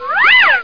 00101_Sound_Up-Down.mp3